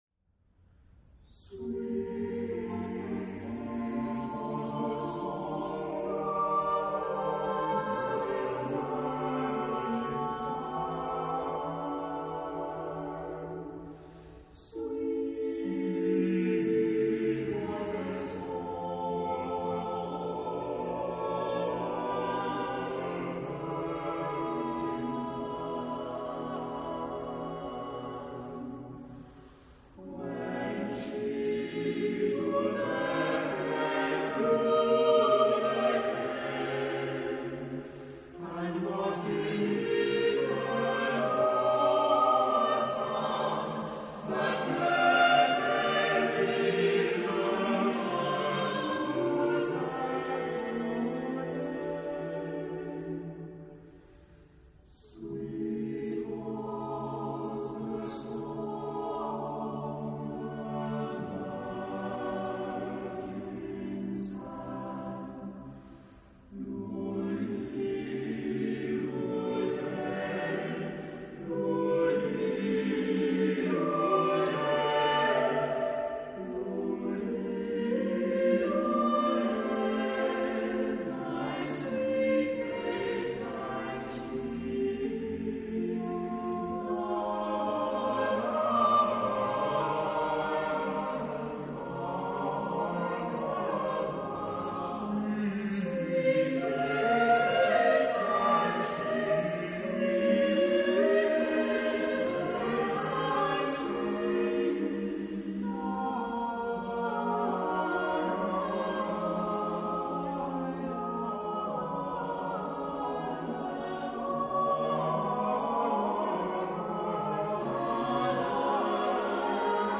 Genre-Style-Form: Sacred ; Choir
Type of Choir: SATB (div)  (4 mixed voices )
Consultable under : 20ème Sacré Acappella